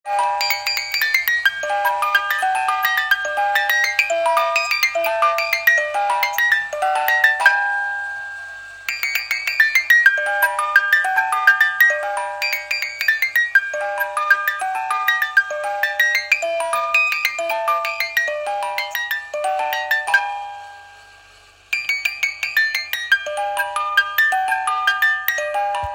Dekorácie postavy ženícha a nevesty sa dajú použiť na zavesenie, alebo postavenie na podstavec, alebo ponechaním na kruhovej podeste, ktorá sa dá natiahnuť, otáča sa a cca 60 sekúnd hrá radostnú melódiu.
Postavičky ženícha a nevesty sú doplnené hracím strojčekom.